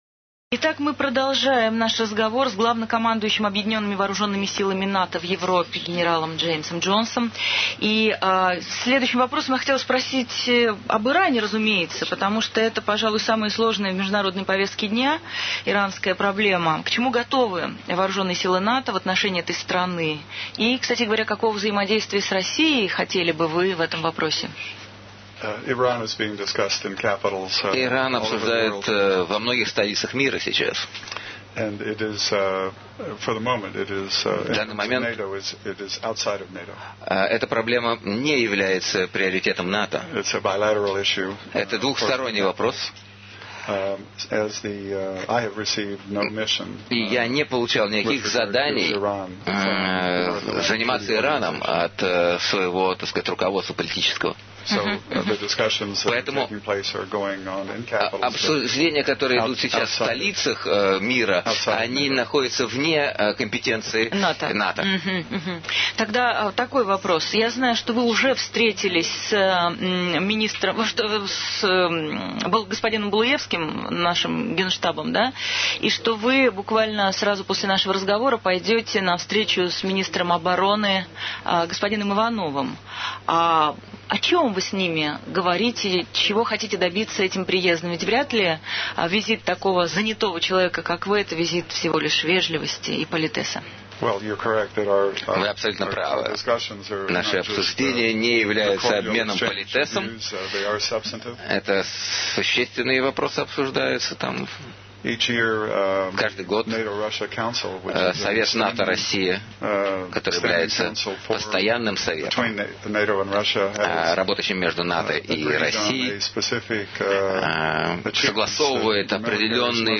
20 апреля 2006 г. Гость - Джеймс Джонс. Сокращённая версия.